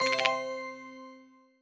Звуки выигрыша
Звук триумфа в игре